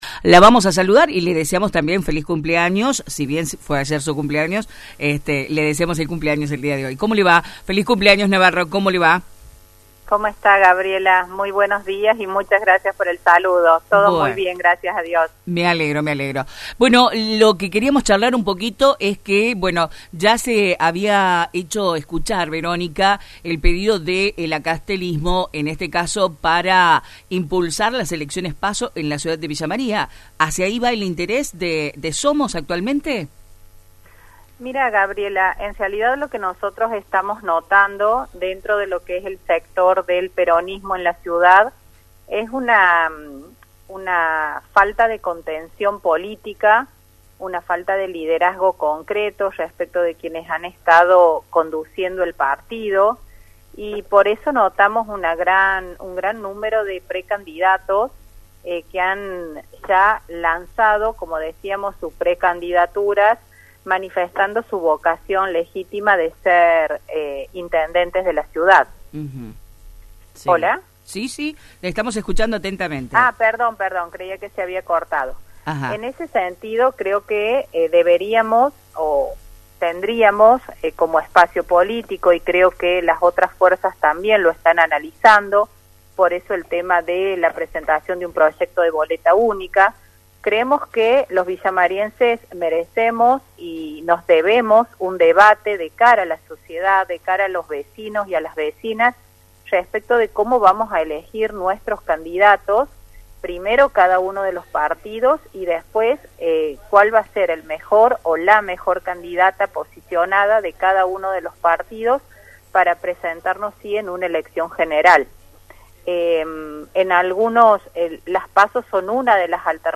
Este jueves, en una entrevista exclusiva con «La Mañana Informal» por Radio Centro, la ex concejal Verónica Navarro respondió algunas preguntas sobre la actualidad política de Villa María.